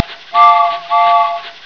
snd_2766_Train.wav